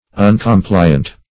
uncompliant - definition of uncompliant - synonyms, pronunciation, spelling from Free Dictionary